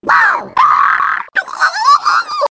One of Wiggler's voice clips in Mario Kart 7